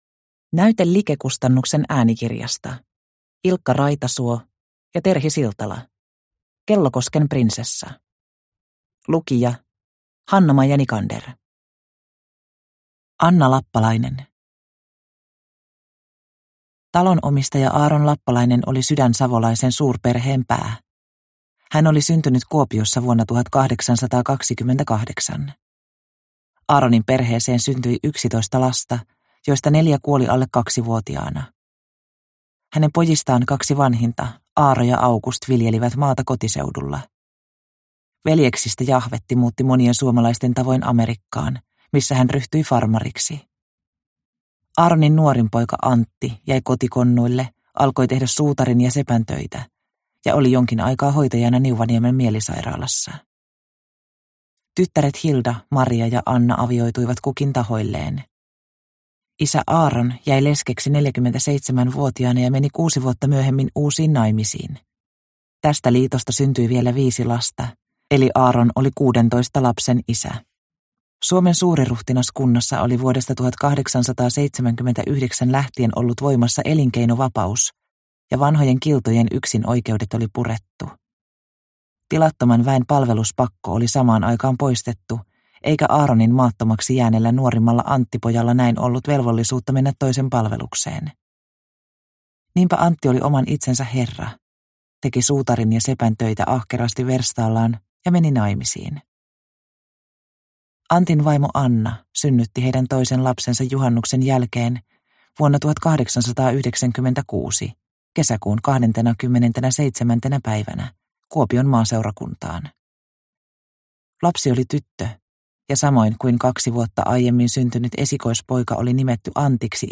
Kellokosken Prinsessa – Ljudbok – Laddas ner